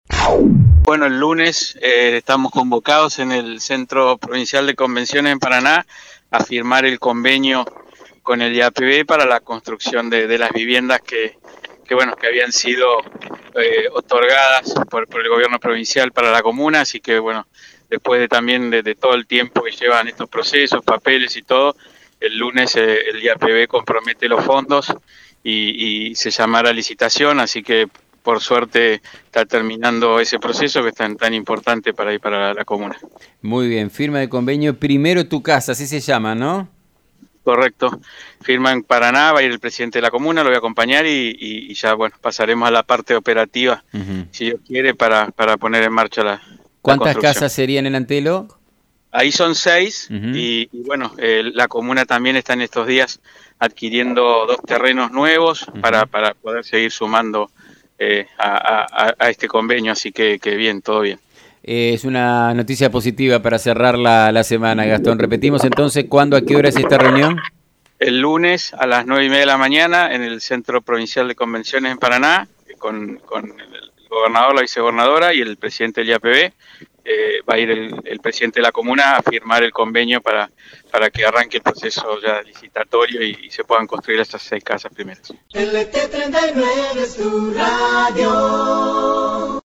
En diálogo con FM 90.3 el senador provincial Gastón Bagnat confirmó que este lunes estará presente en la firma del convenio que el IAPV realizará para la construcción de nuevas viviendas en la Comuna Antelo.